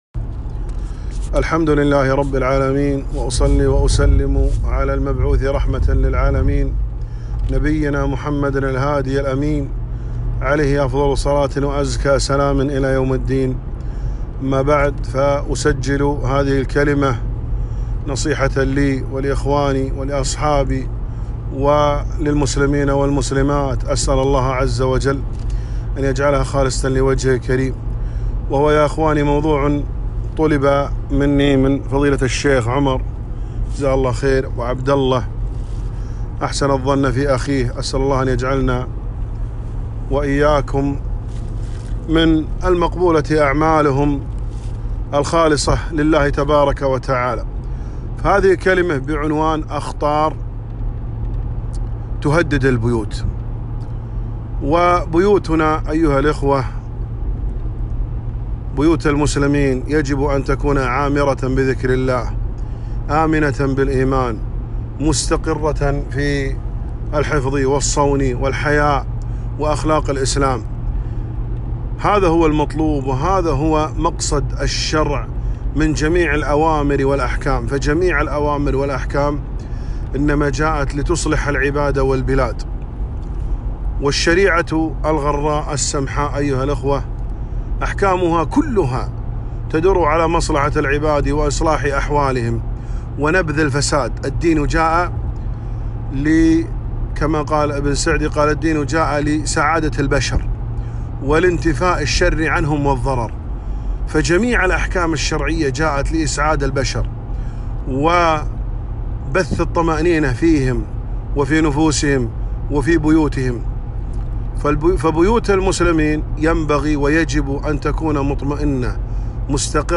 محاضرة - أخطار تهدد البيوت